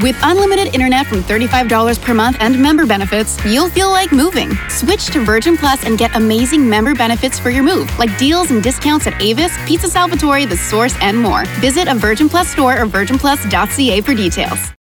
Publicité (Virgin) - ANG